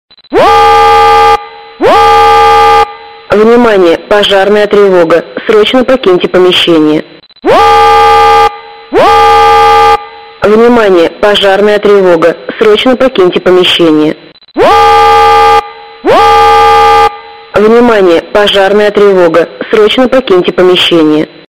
Звуки сигнала тревоги
На этой странице собрана коллекция звуков сигналов тревоги разной длительности и тональности.
Сигналы тревоги – Оповещение о пожаре с голосовым сообщением